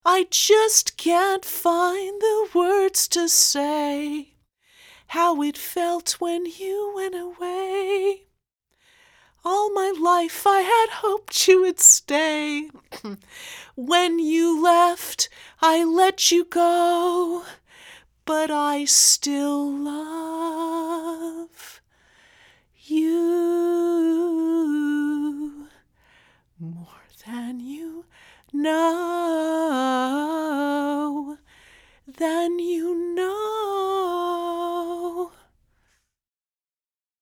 I tried to continue singing but my vocal chords were tight.
The solo followed and I was weeping instead of singing.
I’m actually going to share those 37 seconds of my emotional Take 4.